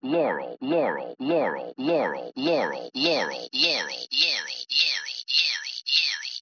Audio S4. A sequence going from lowpass to highpass (see Fig. 1A). Because of context effects, the percept of Laurel may persist for sounds that would have been heard as Yanny in isolation.